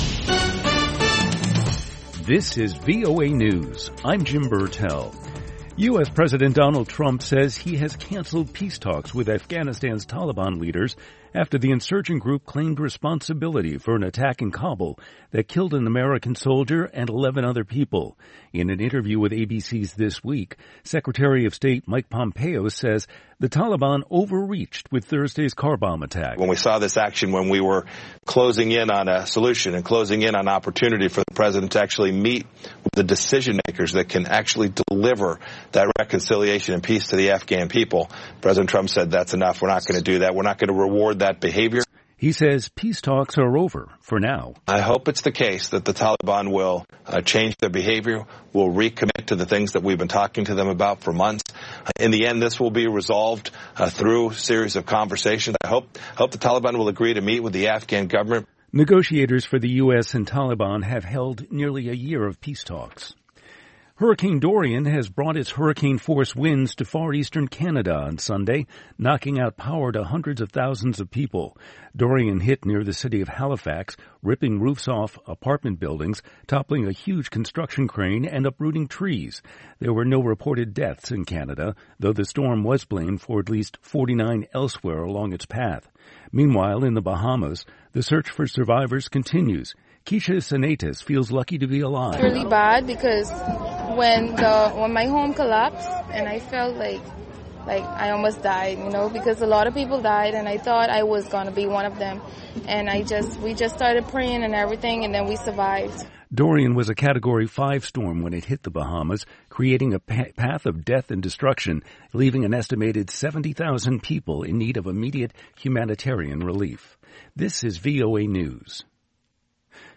VOA Newscasts (2 Minute)